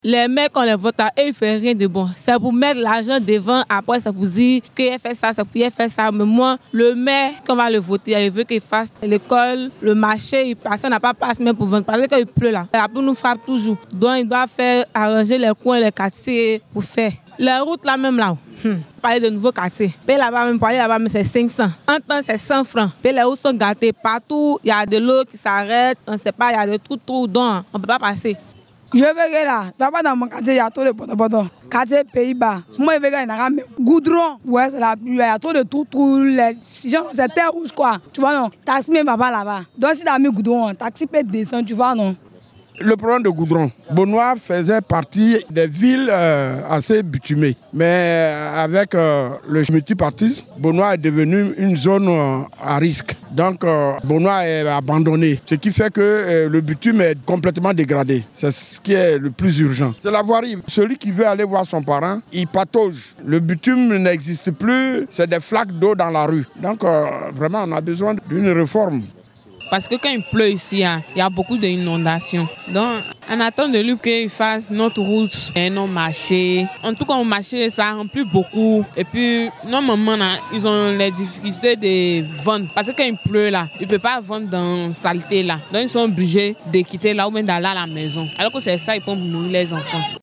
Vox Pop – Attentes des populations de Bonoua vis à vis du prochain maire
vox-pop-attentes-des-populations-de-bonoua-vis-a-vis-du-prochain-maire.mp3